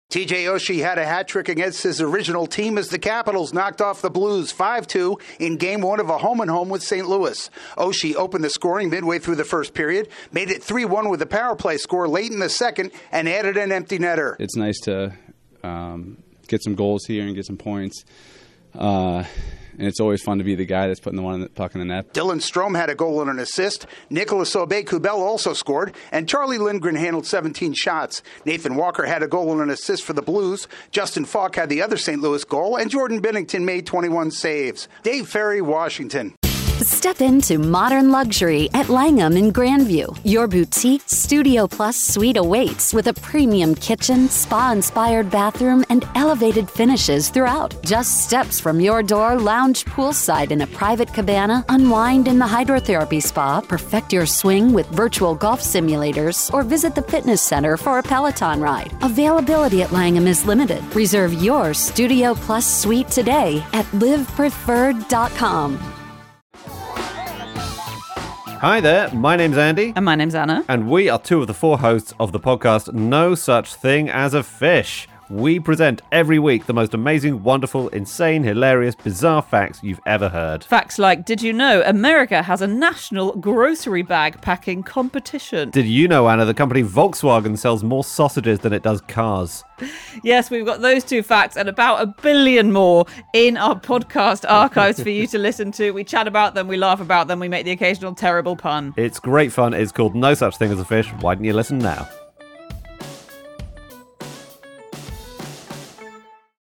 A three-goal performance is the difference as the Capitals down the Blues. AP correspondent